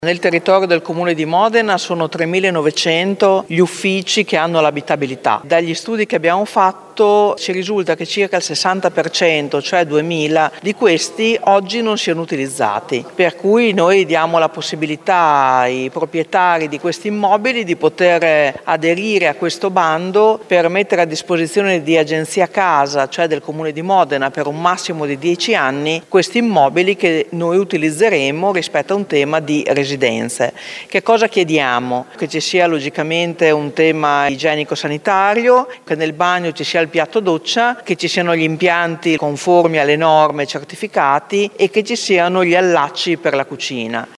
Obiettivo rispondere all’emergenza abitativa e valorizzare gli spazi inutilizzati come spiega Francesca Maletti, assessore alle Politiche abitative: